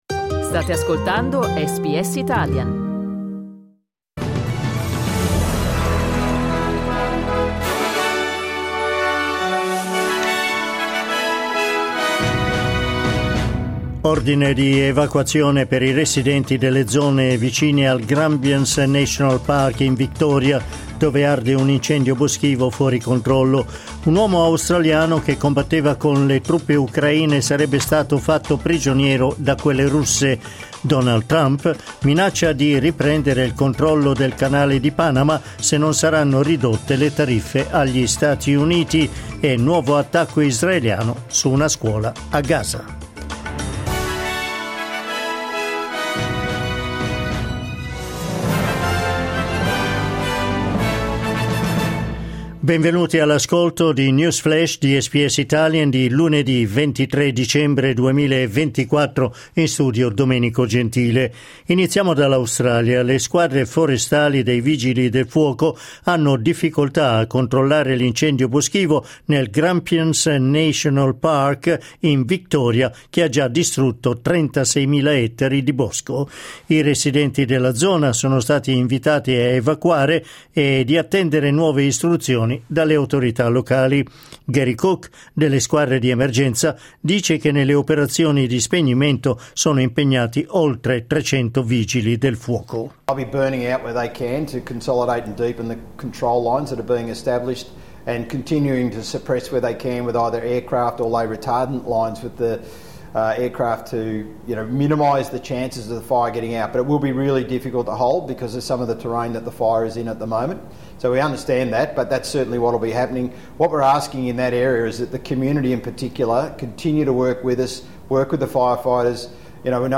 News flash lunedì 23 dicembre 2024
L’aggiornamento delle notizie di SBS Italian.